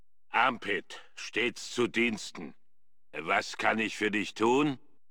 Fallout: Brotherhood of Steel: Audiodialoge
FOBOS-Dialog-Armpit-003.ogg